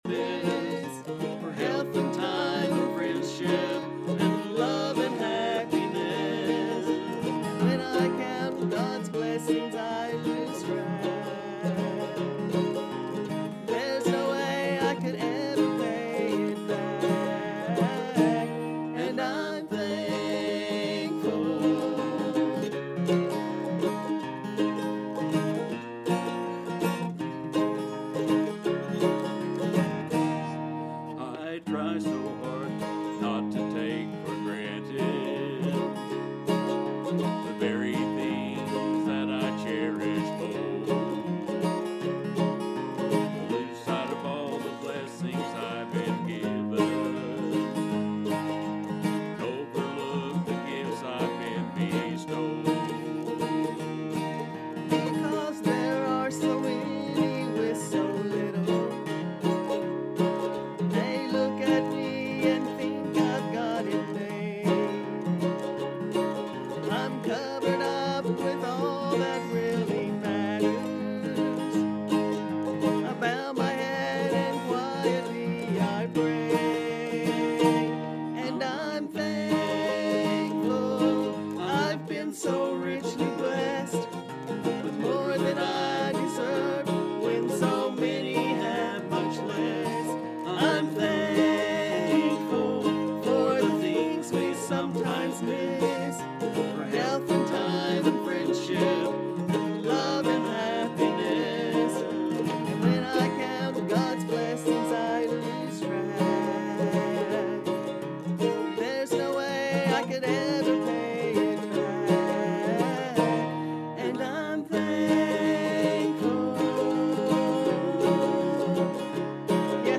Thanksgiving Night of Worship